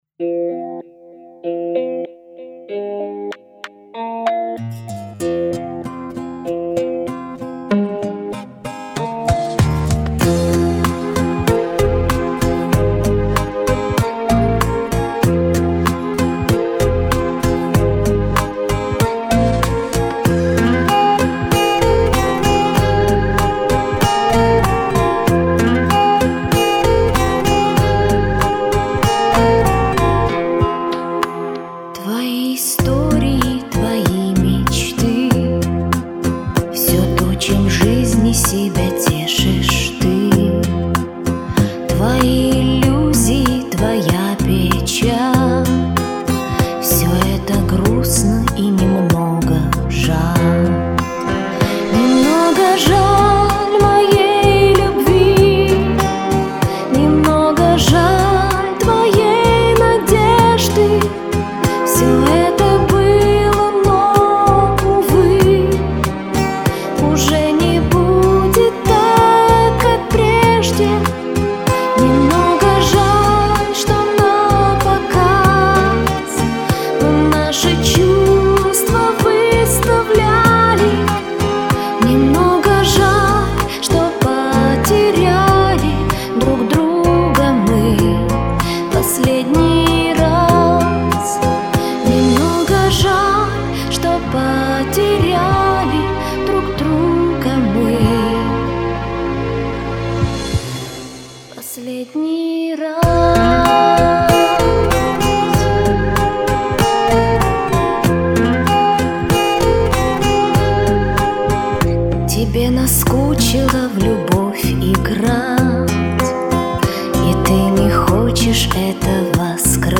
начало заворожило.....боже!как проникновенно. и это так.